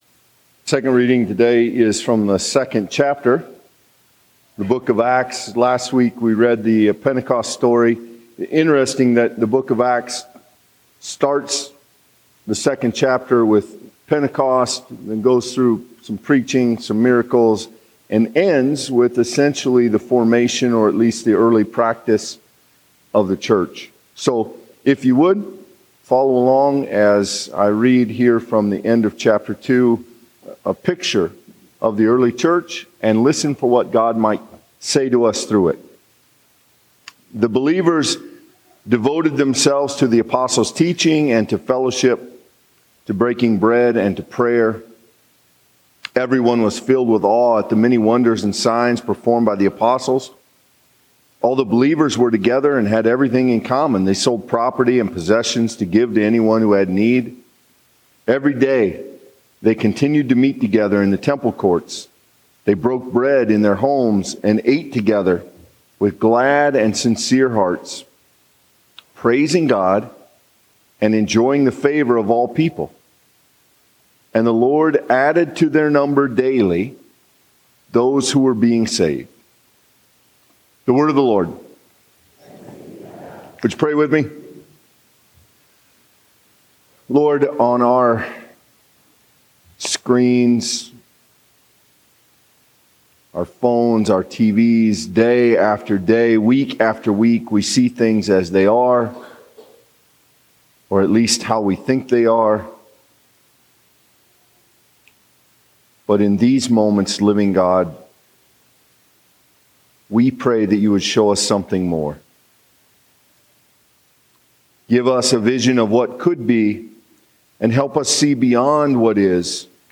A message from the series "Reformed Worship."
The 8:50 worship service at First Presbyterian Church in Spirit Lake.